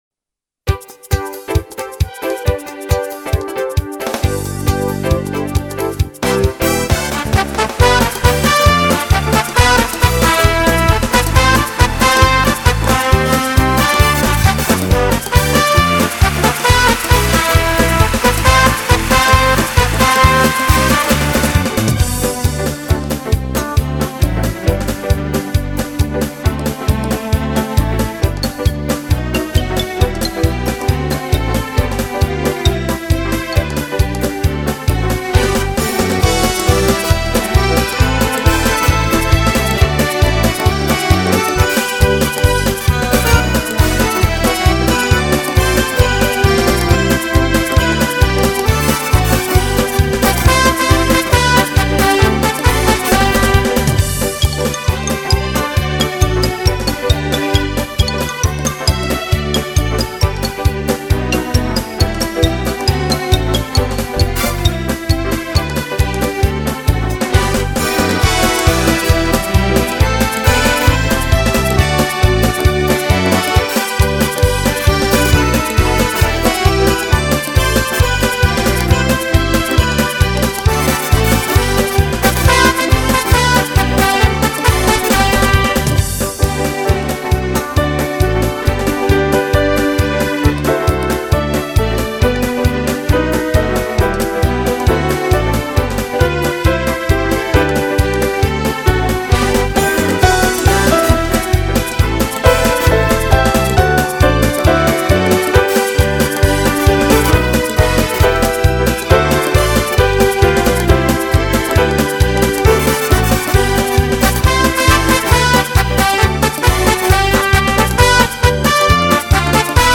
Дитячі